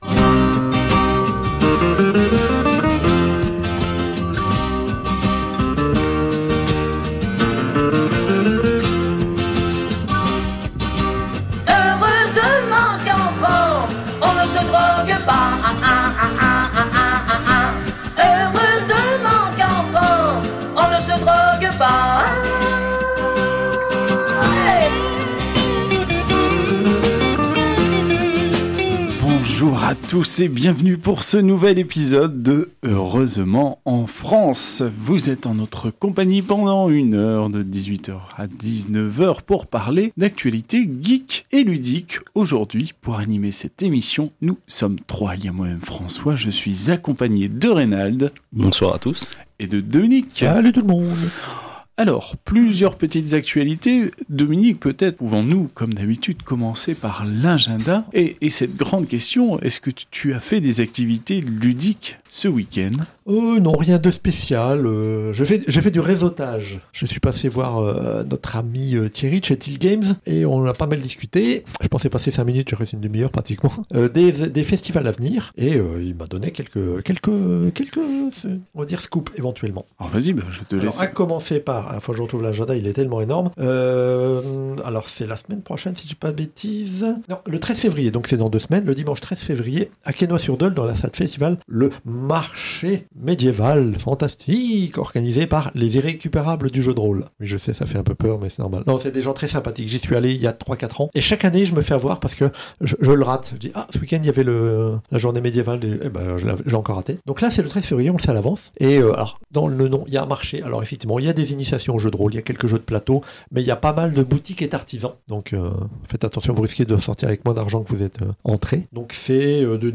Au sommaire de cet épisode diffusé le 30 janvier 2022 sur Radio Campus 106.6 :